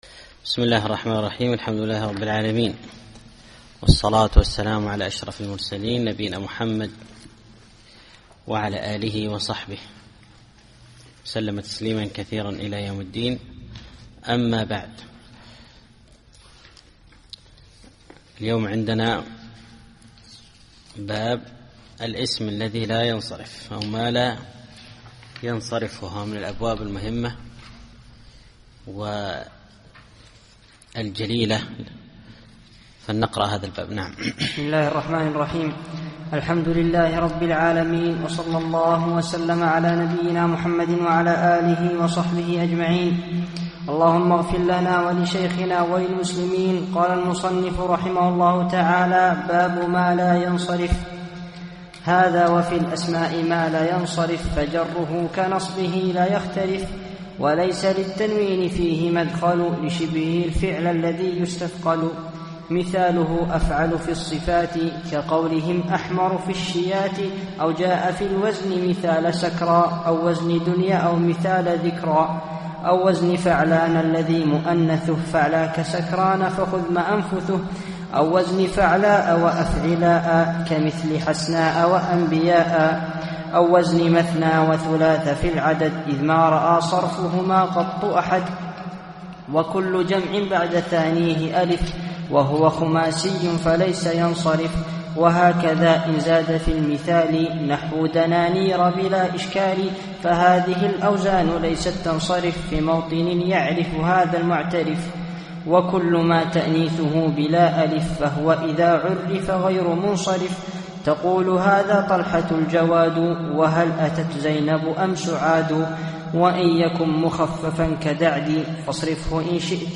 الدرس الثامن والعشرون الأبيات 282-307